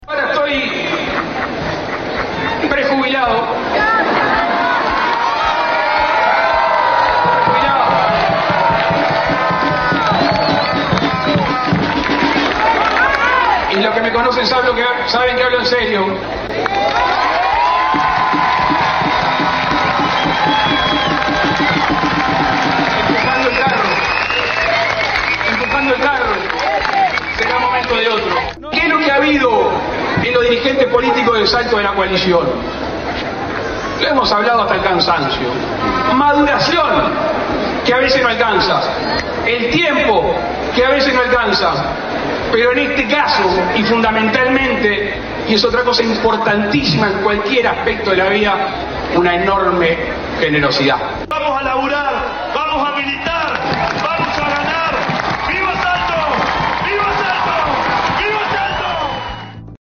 El ex presidente Luis Lacalle Pou estuvo en un acto en Salto, en respaldo al candidato Carlos Albisu.